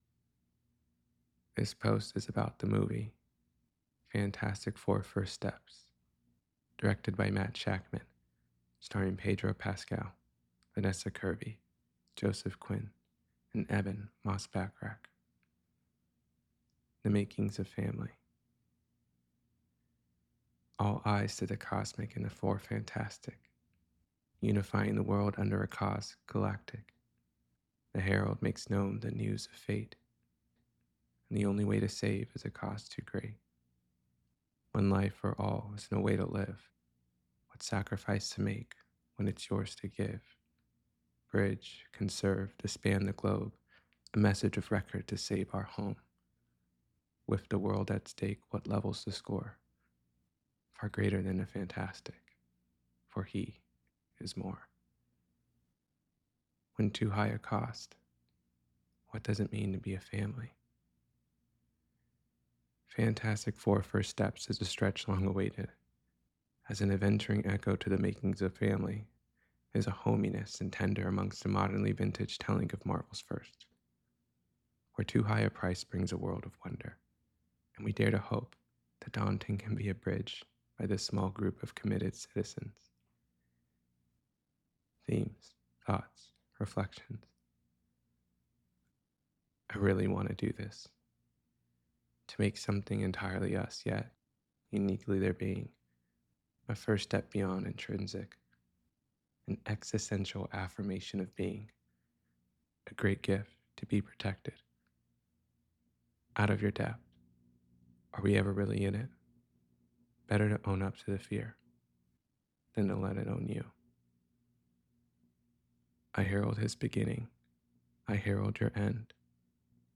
fantastic-four-first-steps-to-know-a-story-reading.mp3